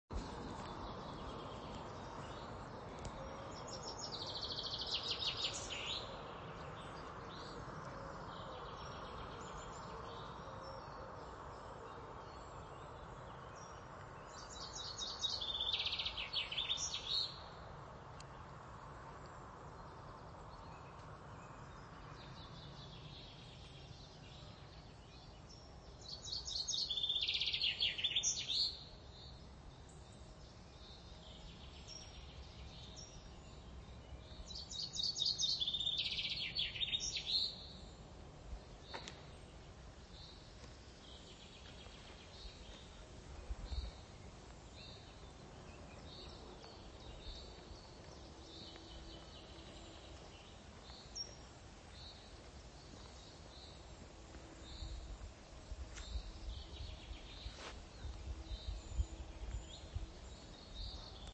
Two common chaffinches exchange greetings in the forest at Vimy Ridge.